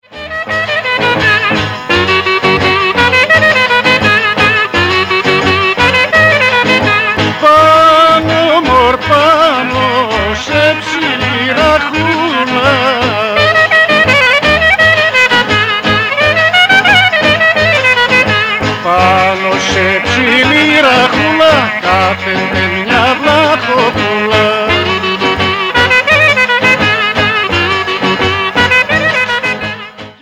Syrtos
lavouto
guitar